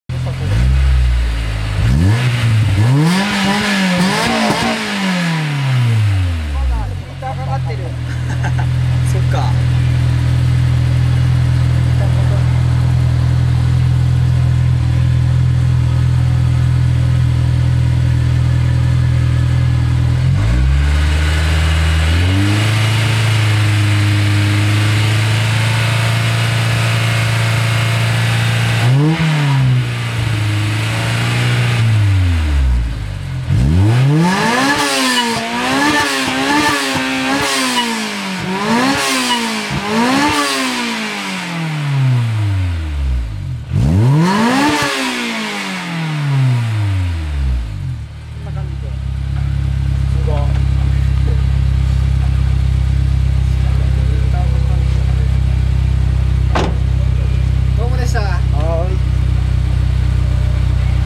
(今回はS2000マフラーサウンド録音オフだよ〜）
マイク：業務用ガンマイク
『※ぴんぽ〜ん 　今回は空ぶかしメインで〜す(^◇^;)』